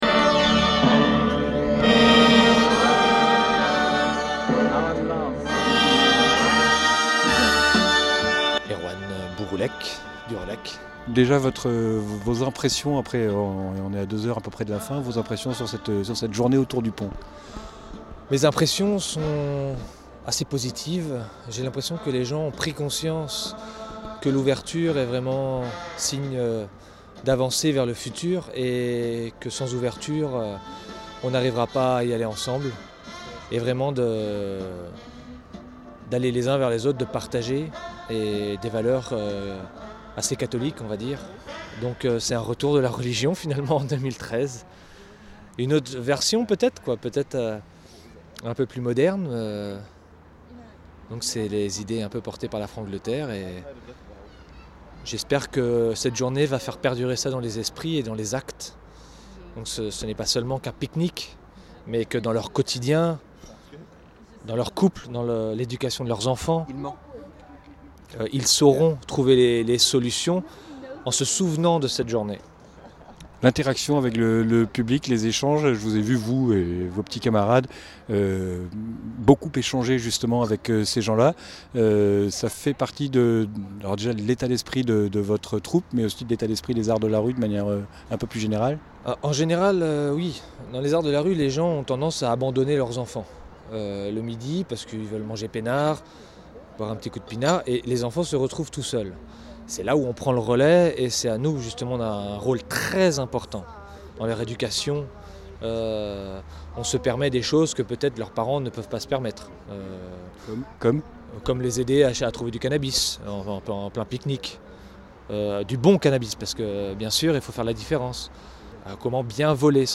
Reportage officiel